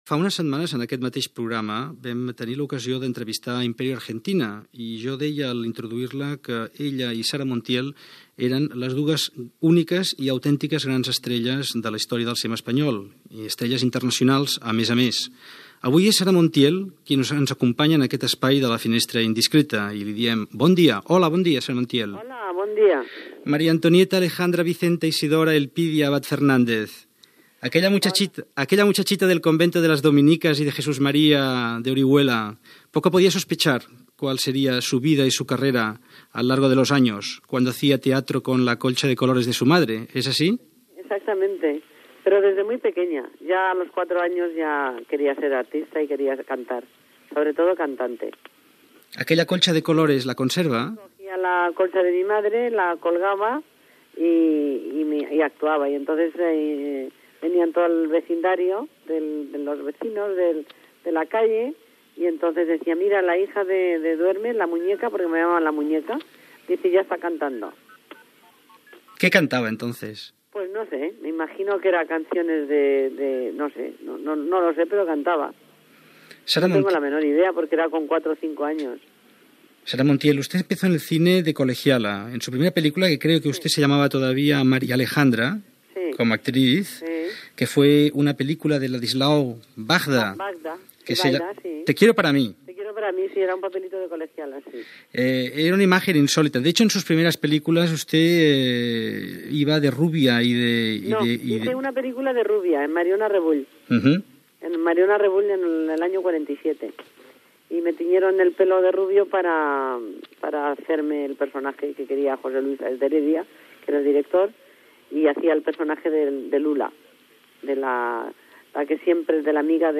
Entrevista a Sara Montiel sobre la seva joventut i els inicis professionals al cinema